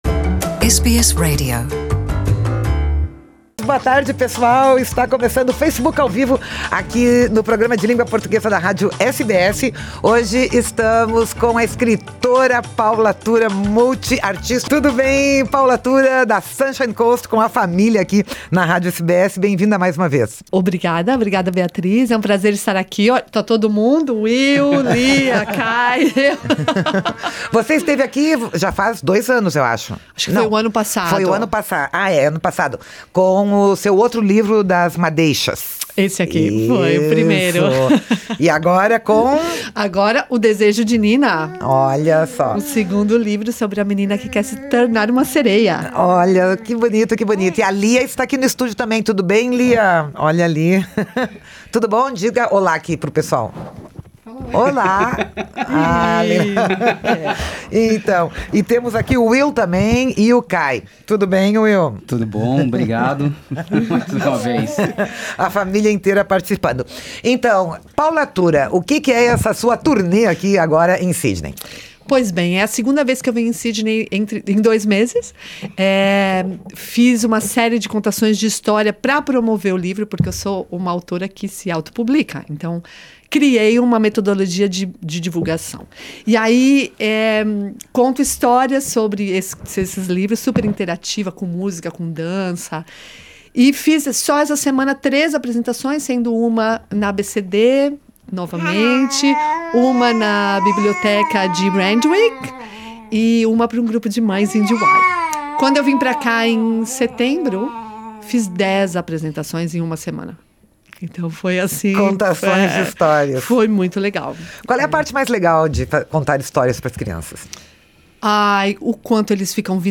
Pedagoga brasileira fala como criar os filhos na Austrália